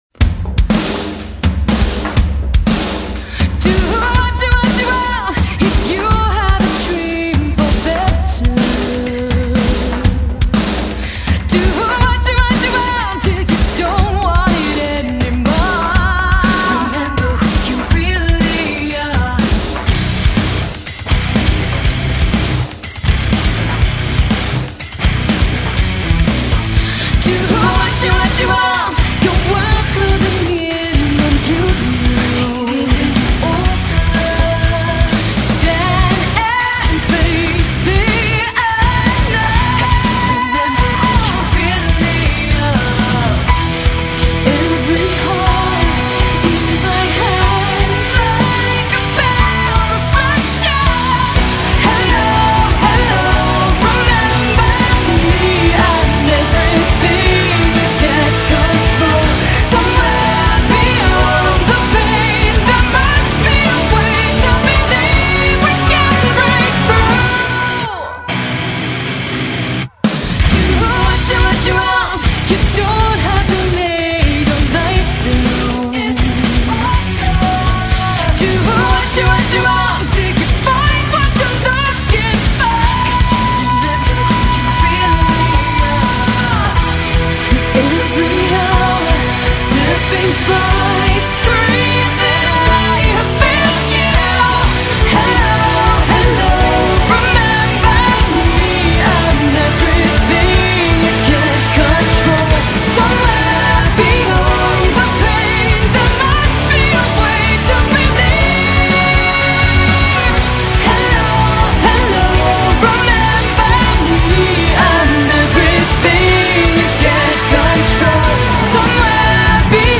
rock музыка